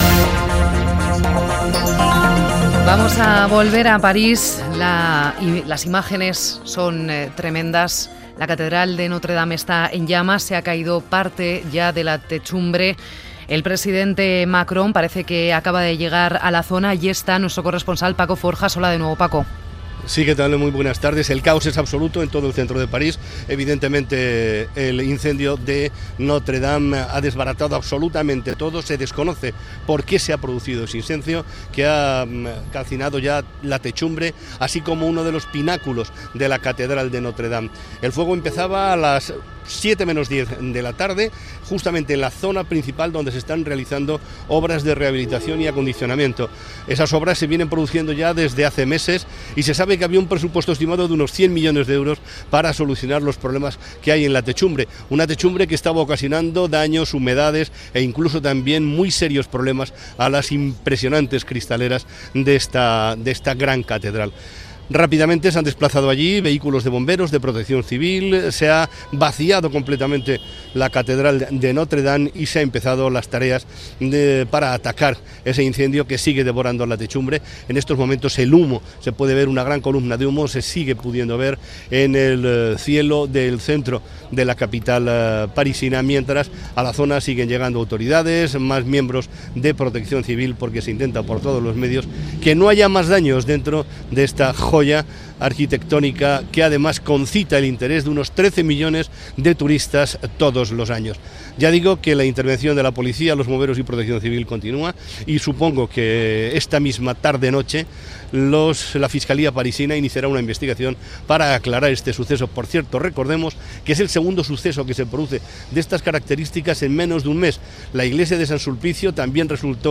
Informació de l'incendi a la catedral de Notre-Dame de París, connexió amb París, declaració d'una testimoni, reaccions internacionals, entrevista a Andrés Perelló de la UNESCO i història i descripció del monument Gènere radiofònic Informatiu